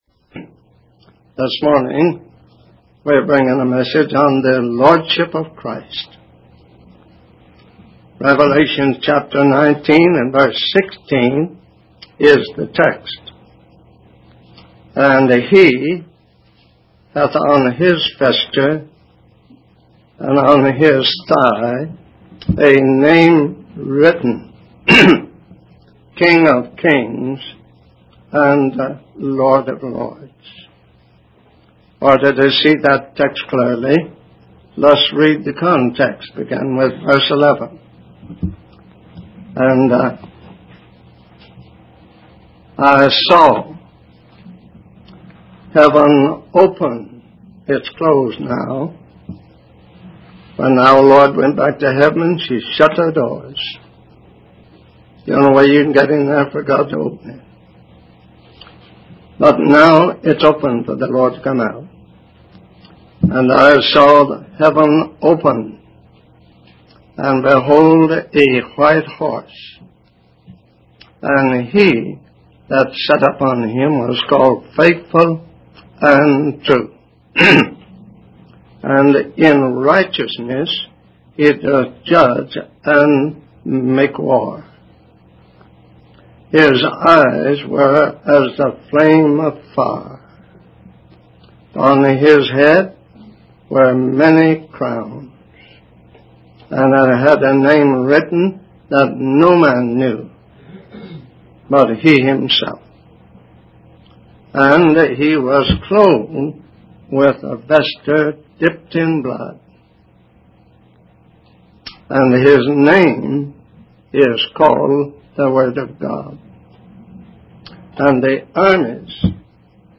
In this sermon, the preacher emphasizes the importance of staying prepared and facing the challenges that come our way, rather than seeking to escape or leave. He uses metaphors such as the wheat and chaff, the good fish and bad fish, and the sheep and goats to illustrate this point. The preacher also affirms that Jesus will be Lord forever, and warns against preaching lies that are not aligned with the truth of the Bible.